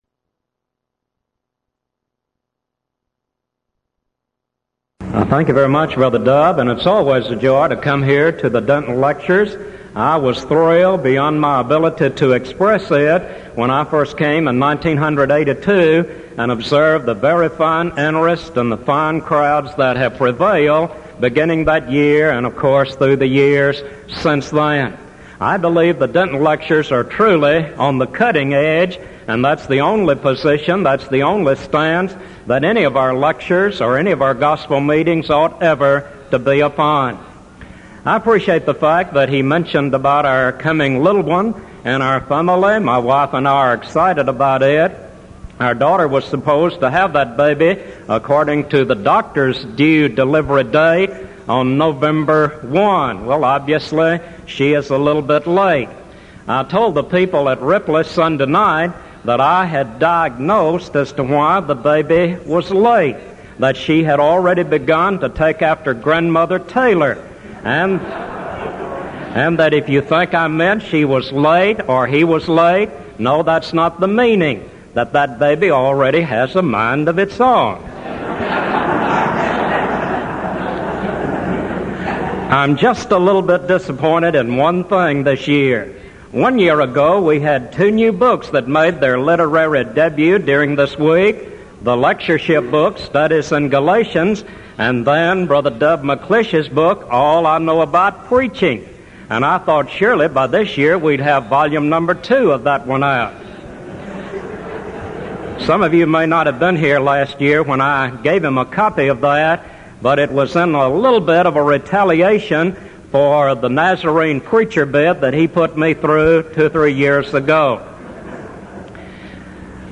Event: 1987 Denton Lectures
lecture